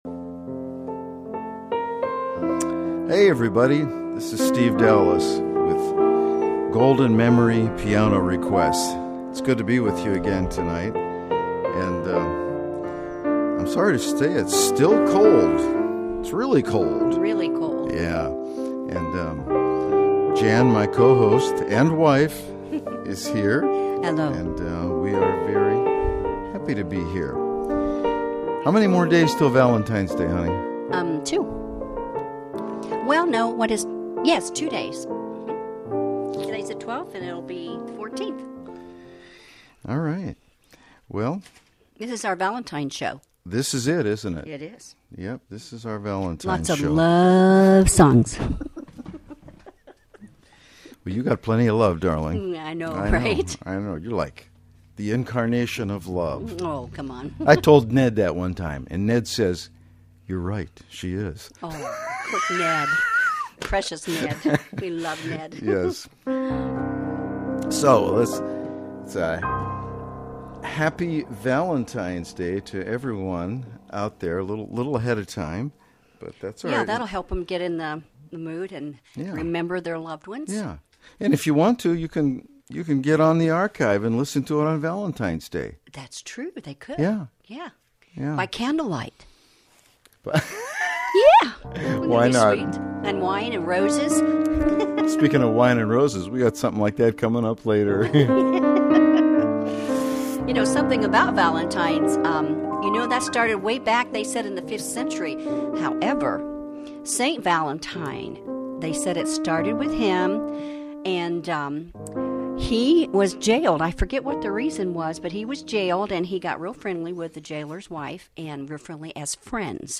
Golden oldies played to perfections!
And certainly give me a call with your song request (see listing of available numbers) and we’ll see if I can play it on the spot!